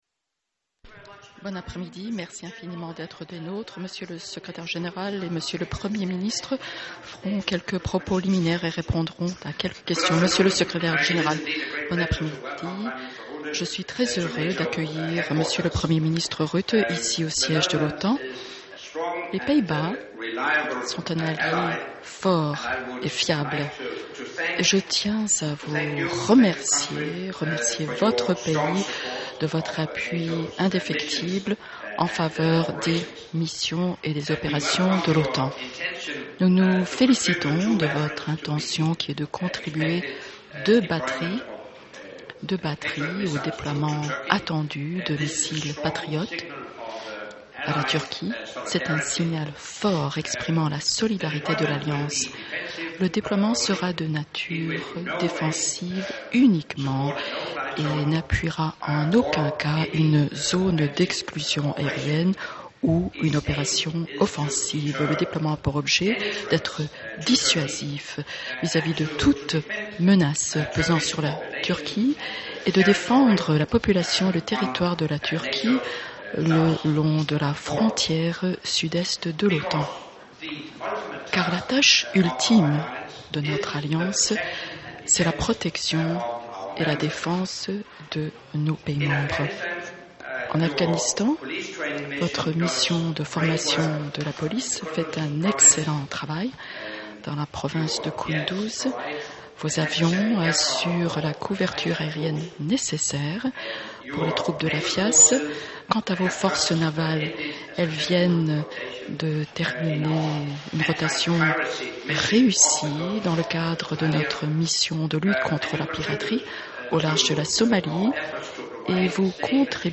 FRENCH - Joint press point with NATO Secretary General Anders Fogh Rasmussen and the Prime Minister of the Netherlands, Mark Rutte 13 Dec. 2012 | download mp3 ORIGINAL - Joint press point with NATO Secretary General Anders Fogh Rasmussen and the Prime Minister of the Netherlands, Mark Rutte 13 Dec. 2012 | download mp3